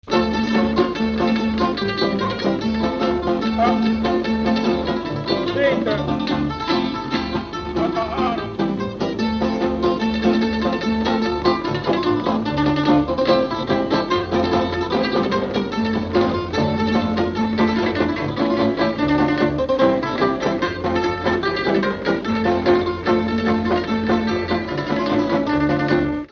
Dallampélda: Hangszeres felvétel
Dunántúl - Verőce vm. - Szentlászló
hegedű
tambura (basszprím)
kontra
cselló
bőgő
Műfaj: Kanásztánc
Stílus: 7. Régies kisambitusú dallamok
Kadencia: 1 (1) b3 1